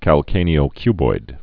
(kăl-kānē-ō-kyboid)